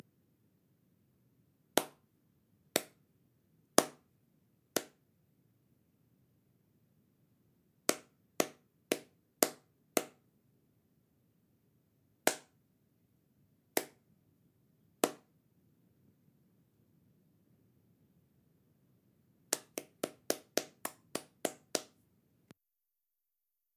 clap01.mp3